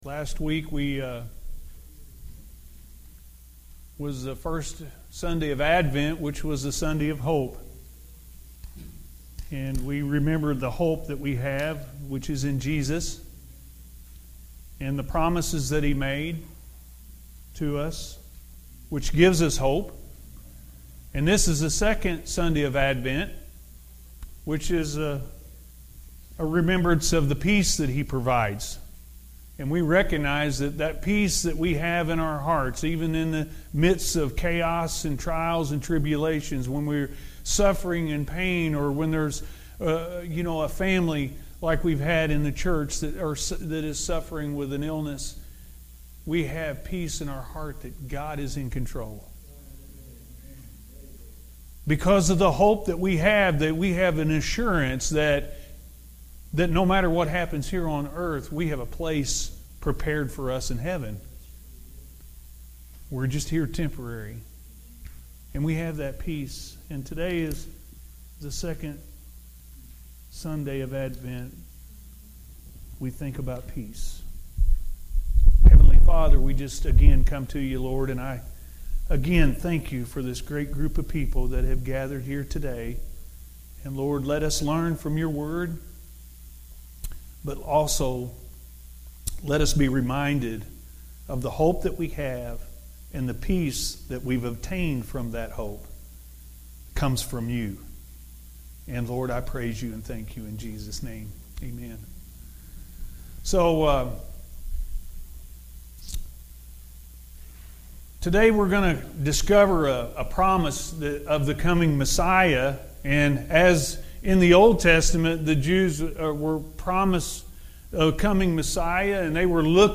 Second Sunday Of Advent Perfect Peace-A.M. Service – Anna First Church of the Nazarene